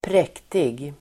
Uttal: [²pr'ek:tig]